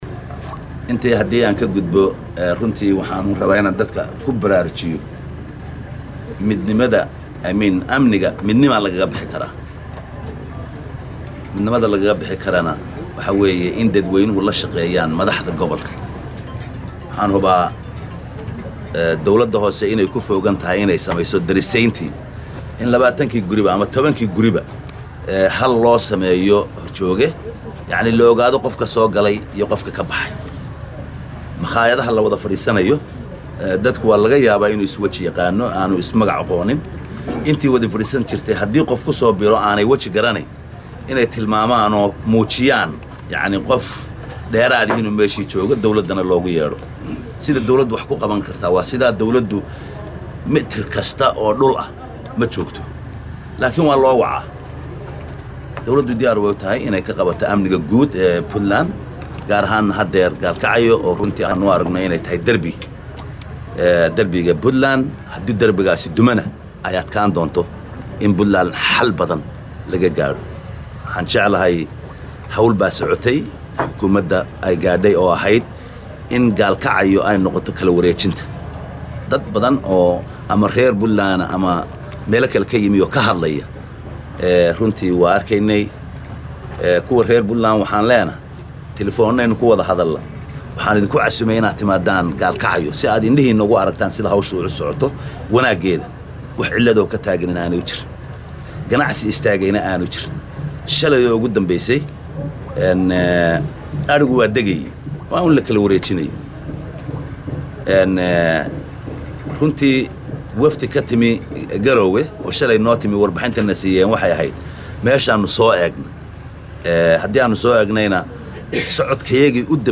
Dhagayso: Madaxweyne ku xigeenka Puntland oo ka hadlay Arimaga Gobolka Mudug.
Xaaladda Amaanka iyo kala wareejinta magaaladda Gaalkacyo isagoo ka hadlayaana waxaa uu yiri.